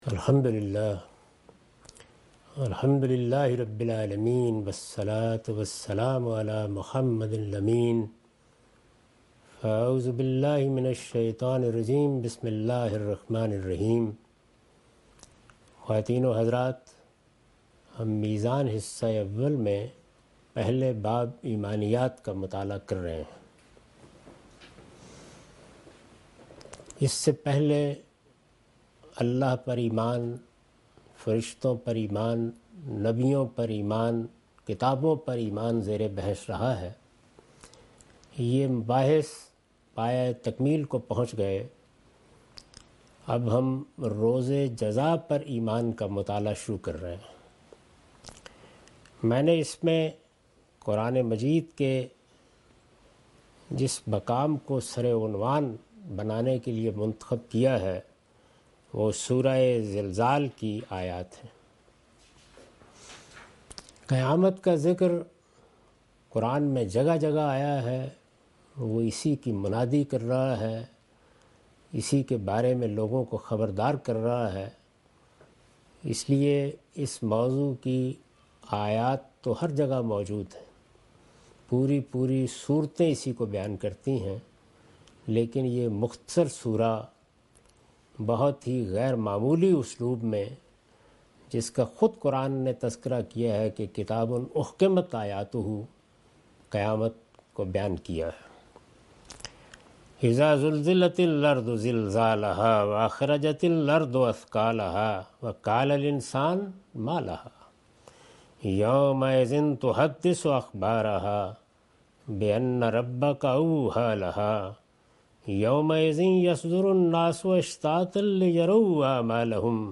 Meezan Class by Javed Ahmad Ghamidi.